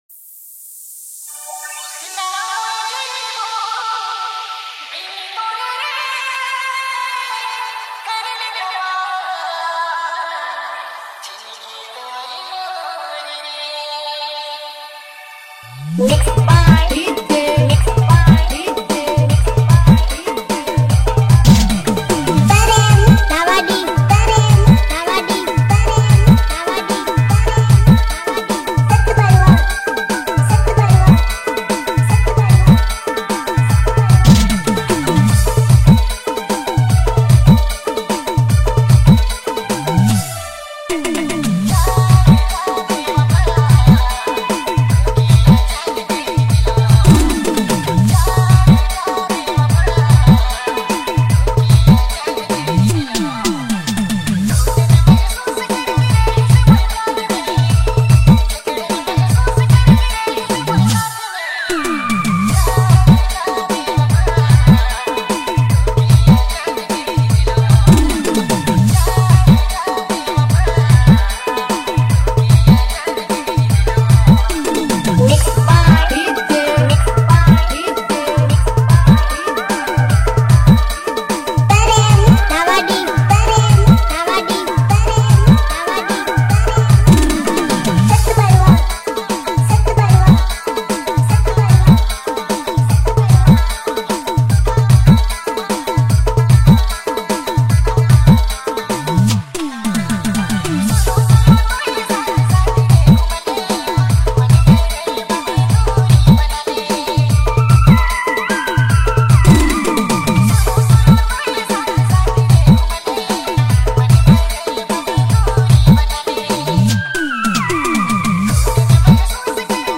New Nagpuri Dj Song 2025